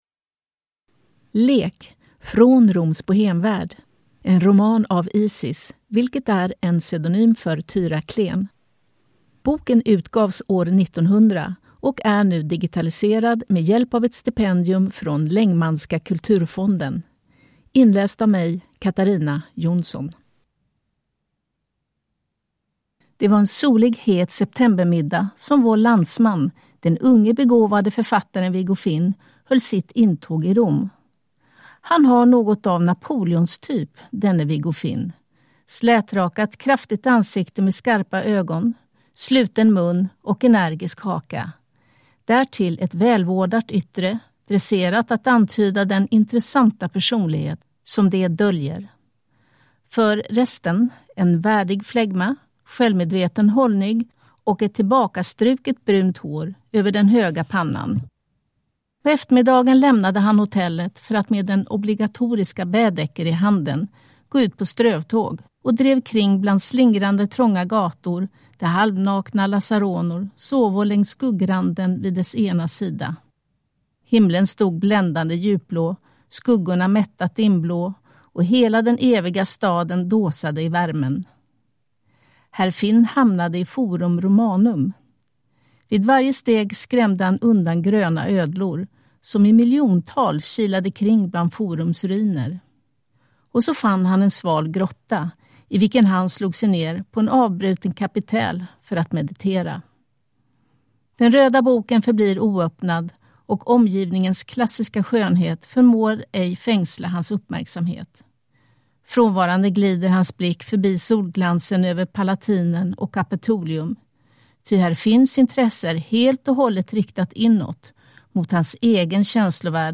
Lek Från Roms bohêmevärld – Ljudbok